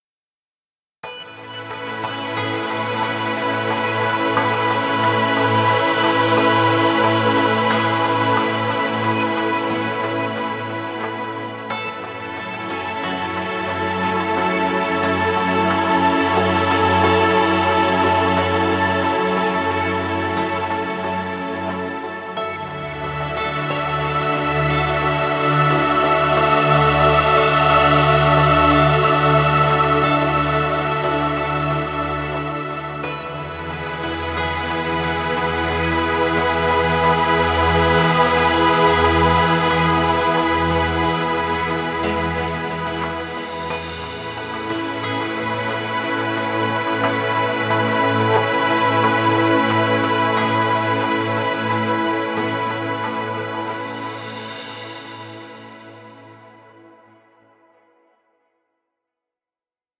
Stock Music.